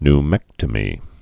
(n-mĕktə-mē, ny-)